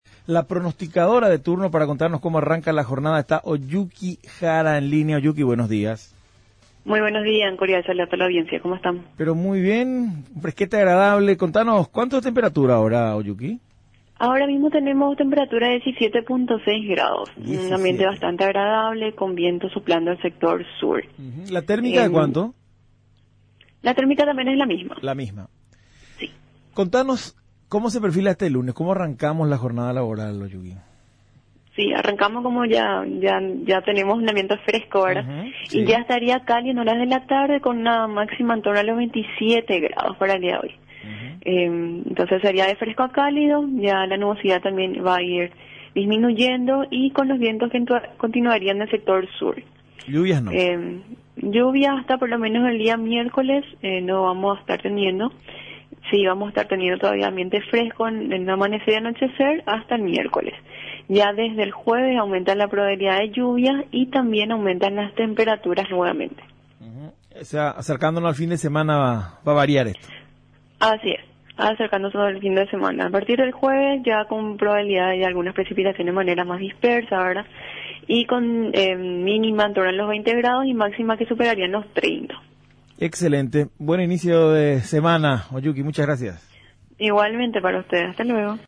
Ambiente fresco a caluroso y sin lluvias telef